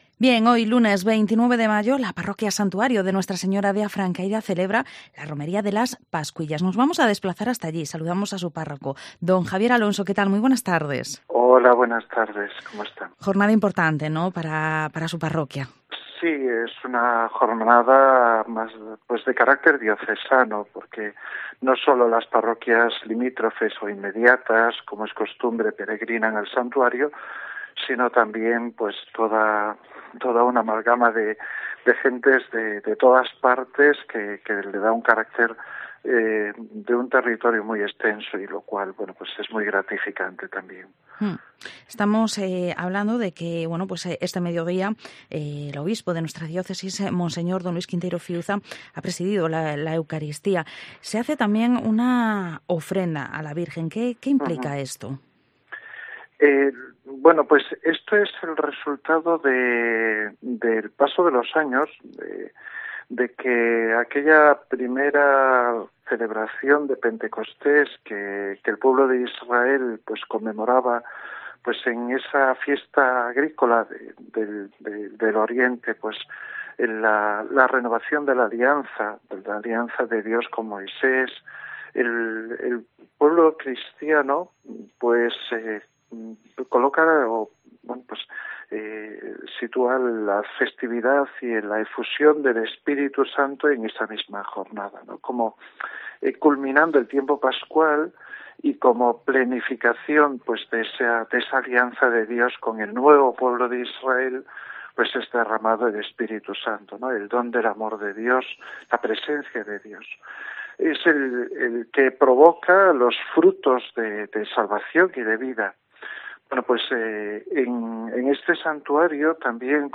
Área Metropolitana Vigo Entrevista